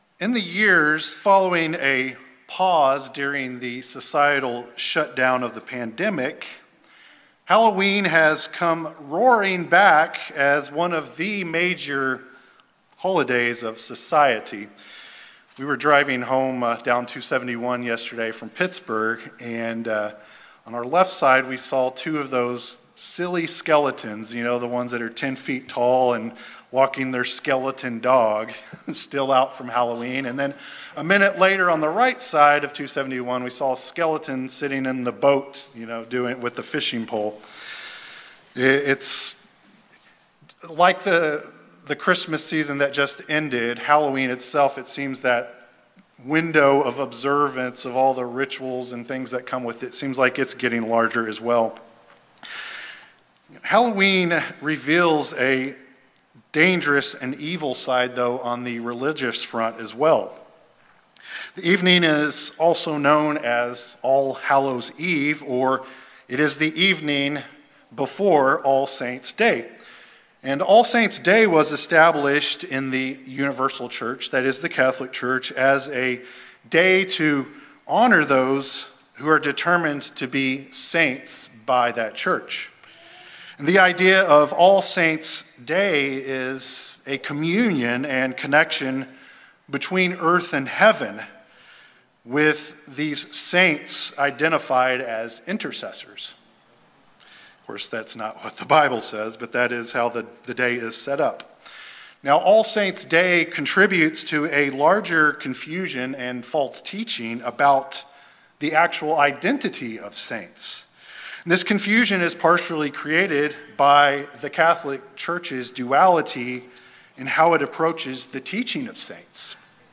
In this message, we go to God's Word to reveal who the saints are and what they are to be doing. We will consider five key characteristics of those whom God calls His saints.